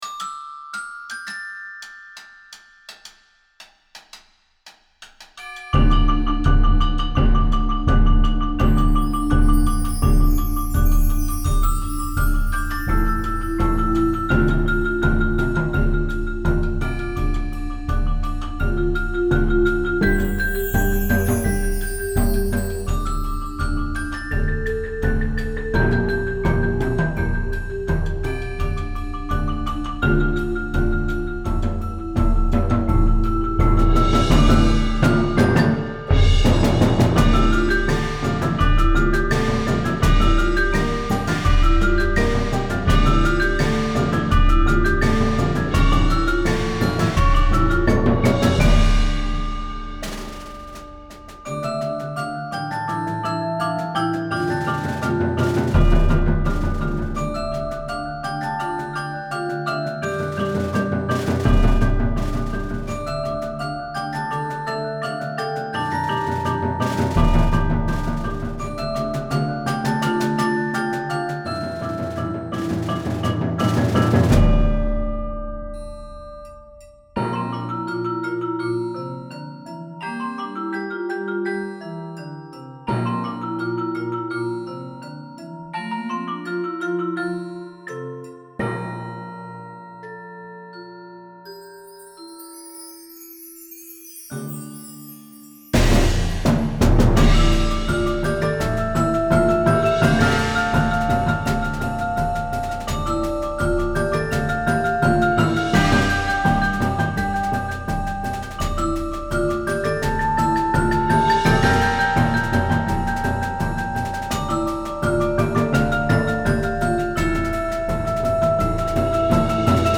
Voicing: 8-13 Percussion